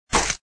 xianrenzhang hit.mp3